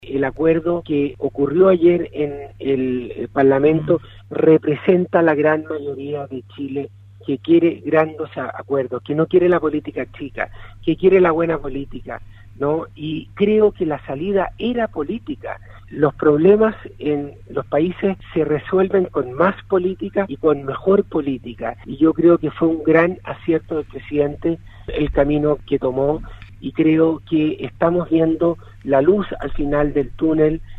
En conversación exclusiva con Radio Sago el Ministro de Agricultura, Antonio Walker explicó que a nivel nacional el sector agrícola jugó un rol fundamental en la mantención del abastecimiento alimenticio en el país, ello gracias al compromiso de la agricultura familiar campesina, que pese a la difícil situación social que se vive en Chile, nunca dejaron de preocuparse por los cultivos y distribución al comercio menor como mayor.